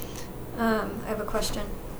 um i have a question.wav